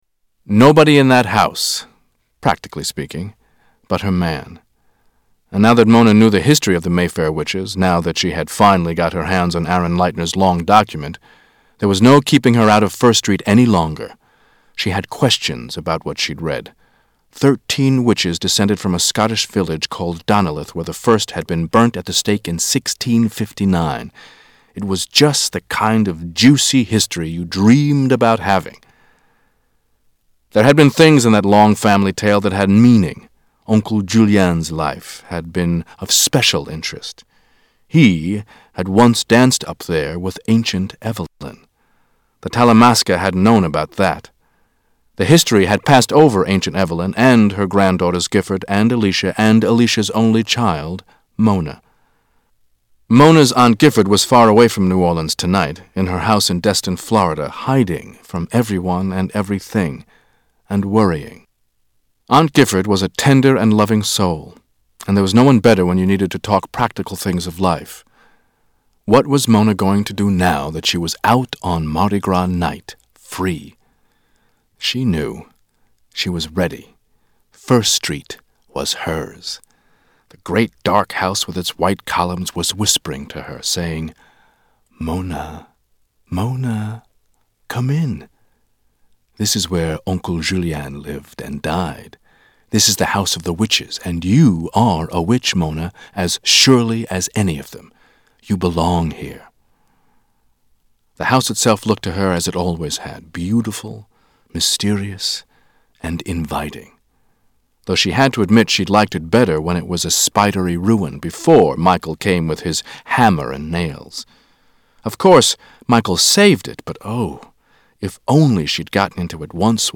Tags: Media Author Anne Rice Interview with the Vampire Audio Books